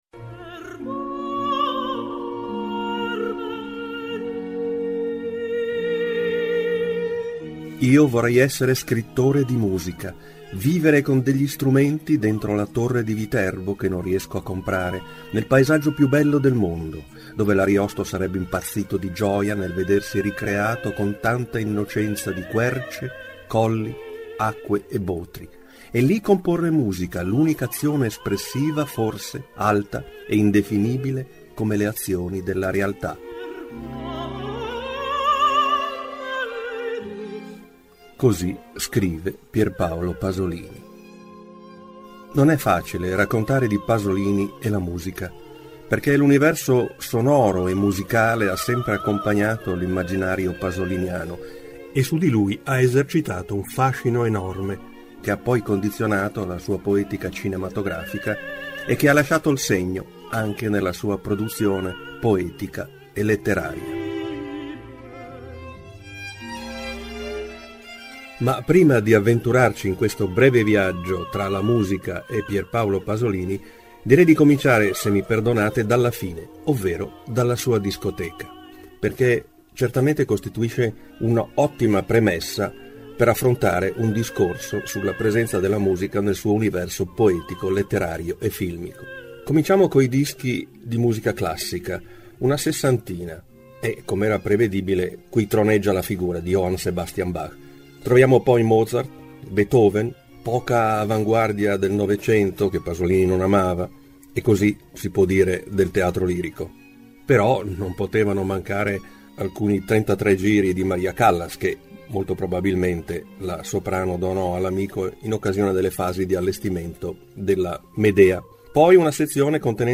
Con le voci di Giovanna Marini, Sergio Endrigo ed Ennio Morricone e dello stesso Pasolini.